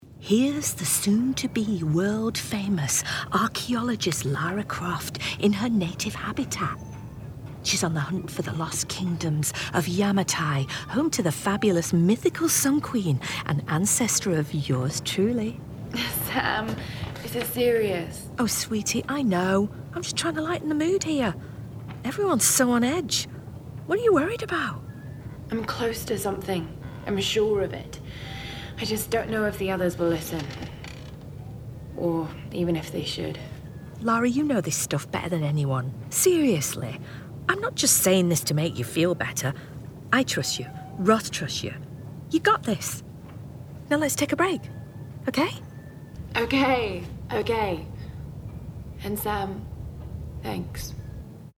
40/50's Northern,